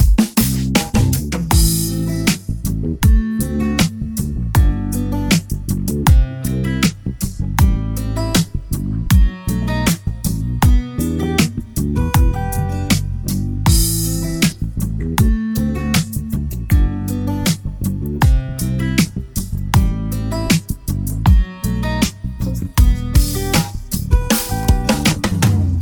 لوپ ۴/۴ سافت تاچ گیتار آکوستیک
demo-soft-touch-4-4-acoustic-guitar.mp3